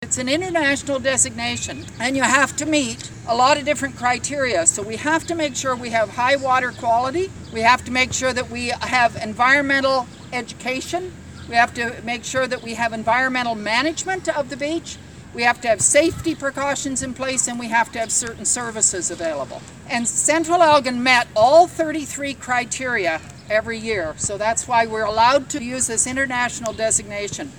During a brief flag-raising ceremony this morning, Central Elgin Mayor Sally Martyn explained the significance of the world-renowned eco-certification.